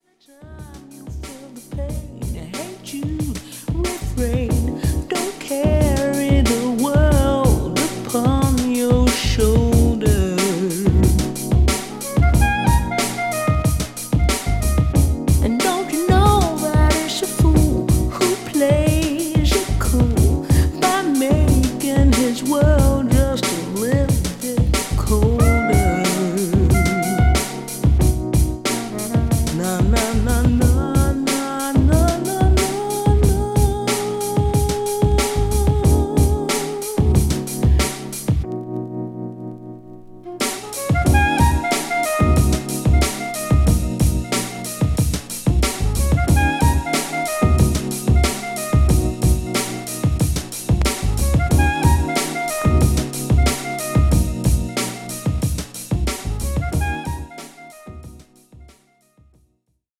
A：Vocal Mix